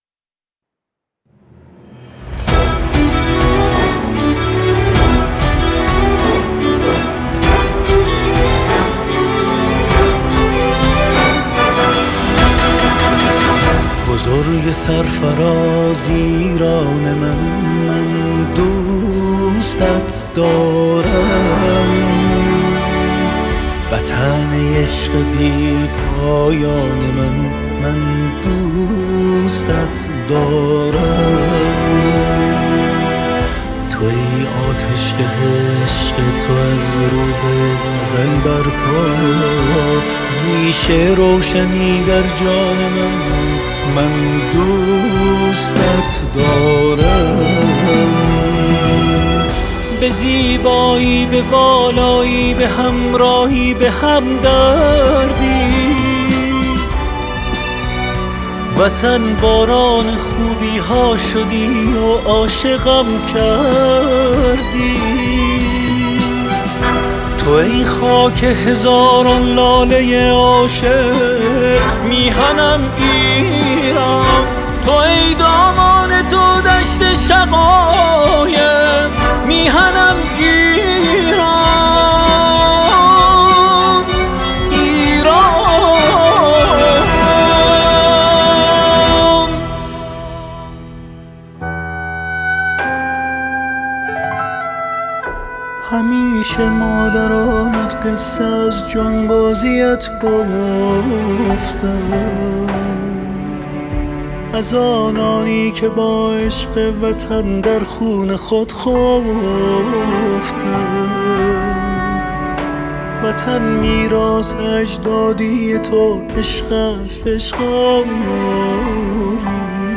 تک‌آهنگ ملی میهنی
موسیقی ارکسترال ایرانی